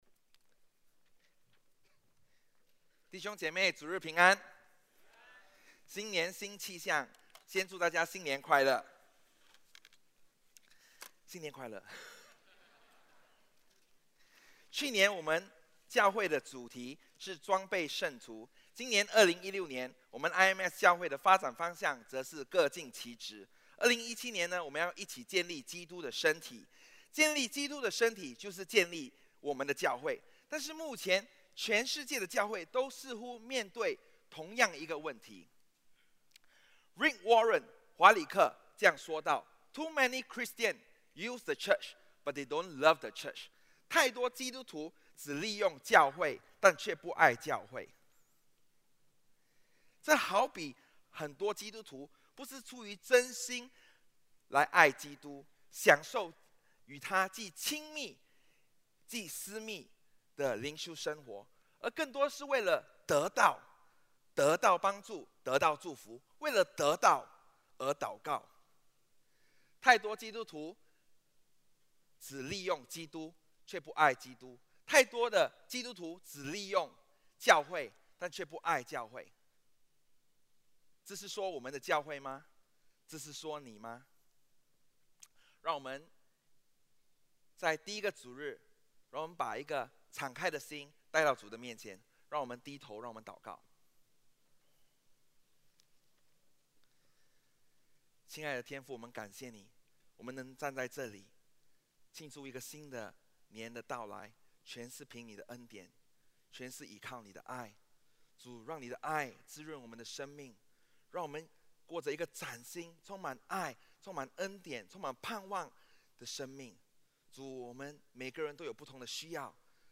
主日证道 | 你的爱去了哪里？